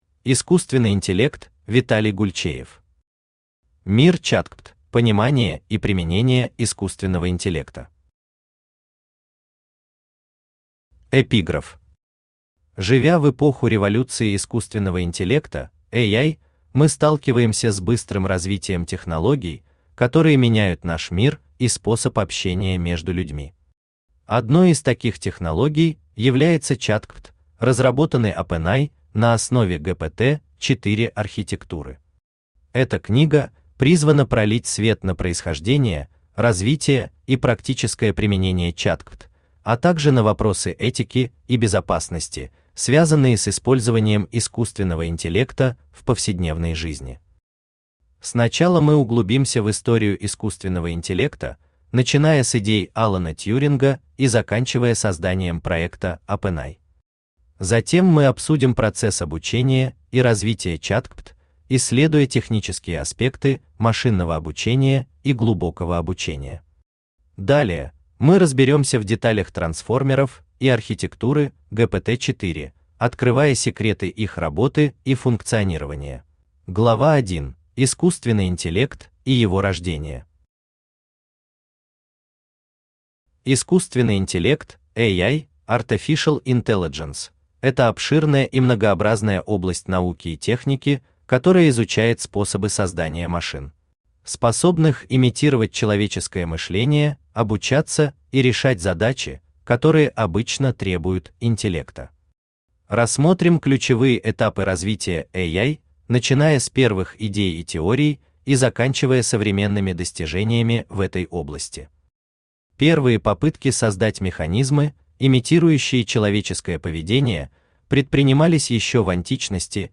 Aудиокнига Мир ChatGPT: Понимание и Применение Искусственного Интеллекта Автор Виталий Александрович Гульчеев Читает аудиокнигу Авточтец ЛитРес.